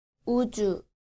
• 우주
• uju